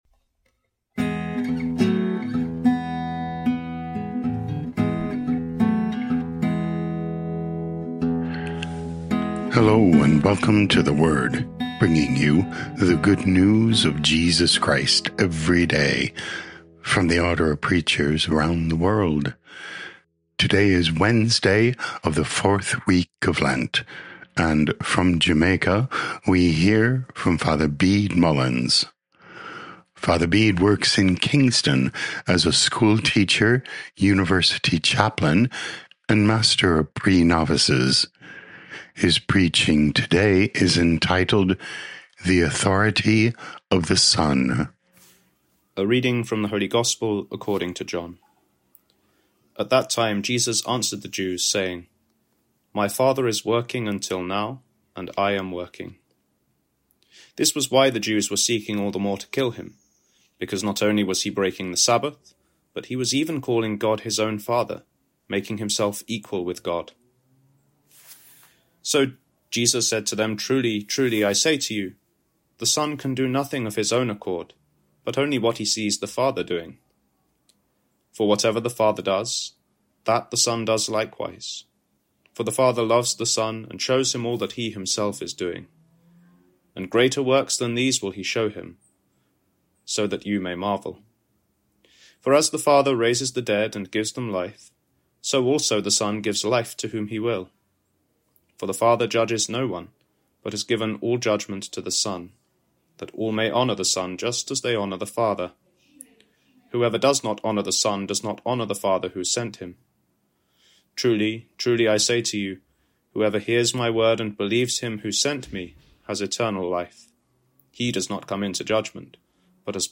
18 Mar 2026 The Authority of the Son Podcast: Play in new window | Download For 18 March 2026, Wednesday of the 4th week of Lent, based on John 5:17-30, sent in from Kingston, Jamaica.